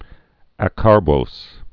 (ăkär-bōs)